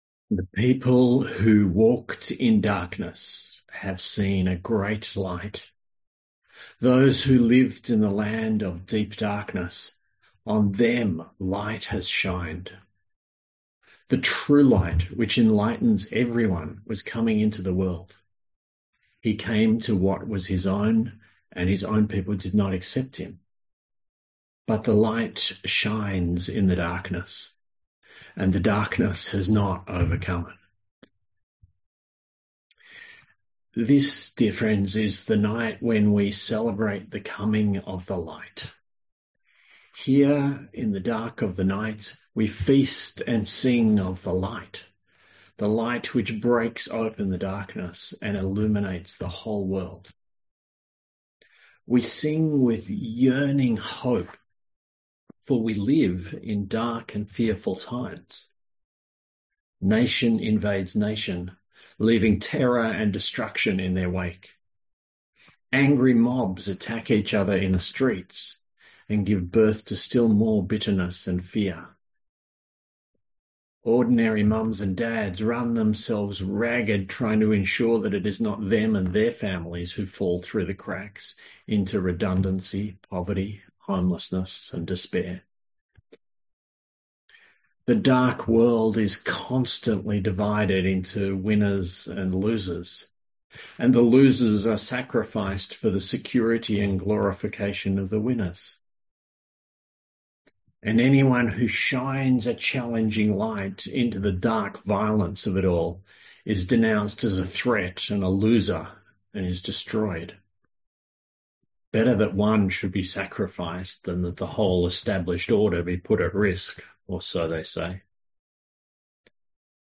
A sermon on John 1:1-14 & Isaiah 9:2-7